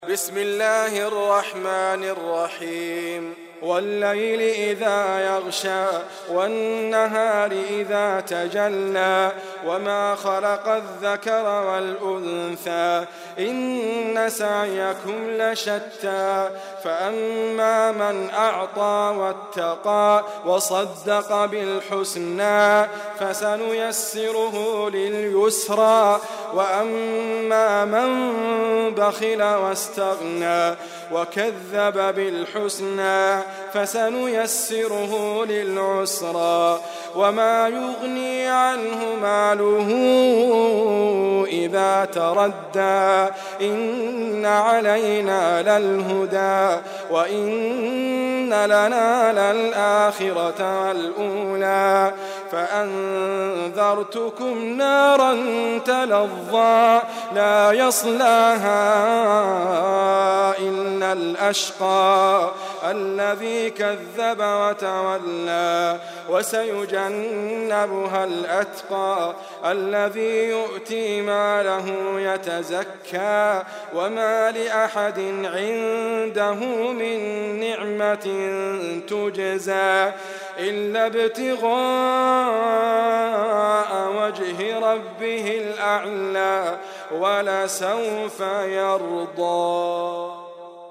القران الكريم -> ادريس ابكر -> الليل